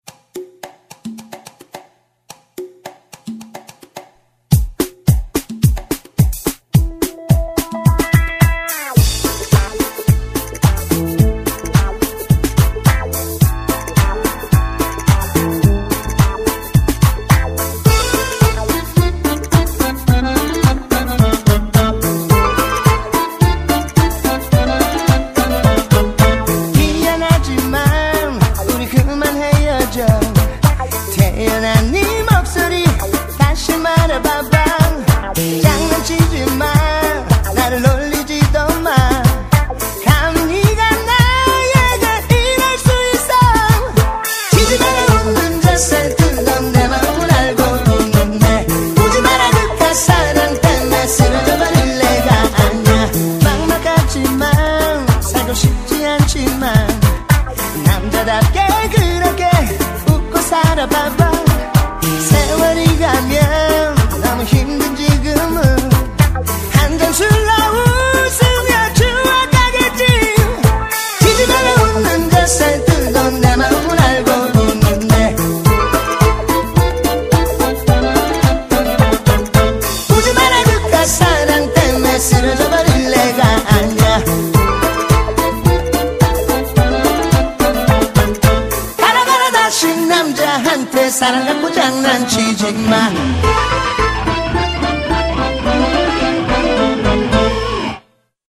BPM107--1
Audio QualityPerfect (High Quality)